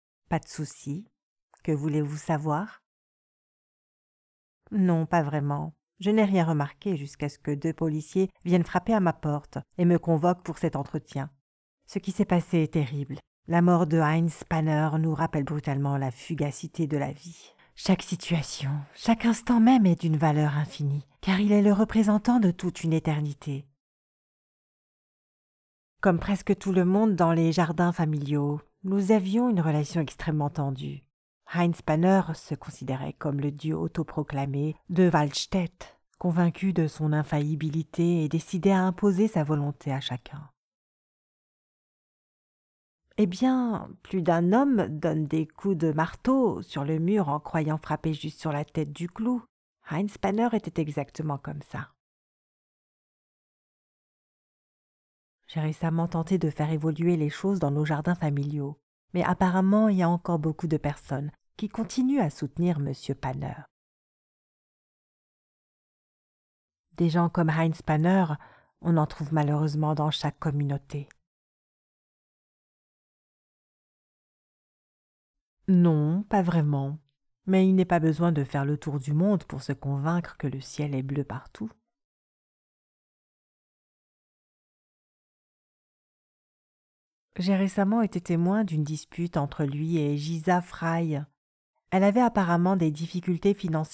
Female
Yng Adult (18-29), Adult (30-50)
Most importantly, I handle all the technical mastering work (-23dB to -18dB| kbps) and formatting in my home studio.
Dialogue